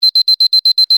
alarm.ogg